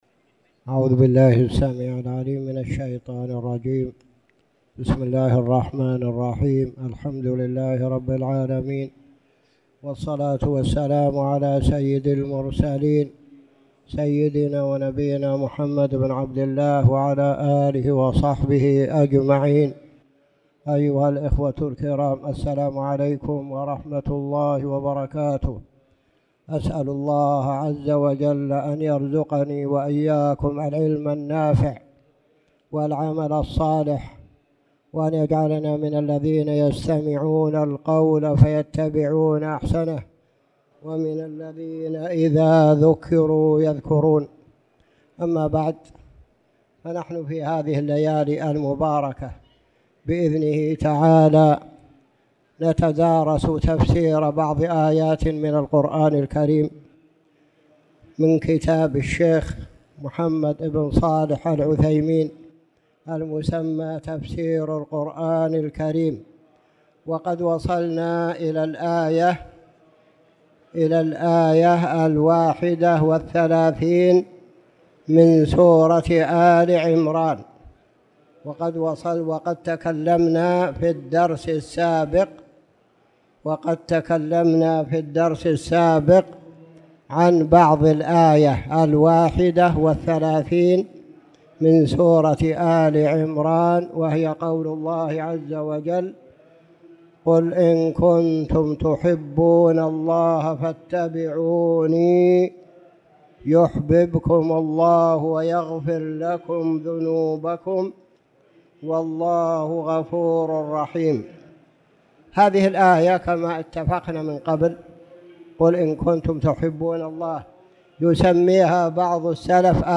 تاريخ النشر ٧ جمادى الأولى ١٤٣٨ هـ المكان: المسجد الحرام الشيخ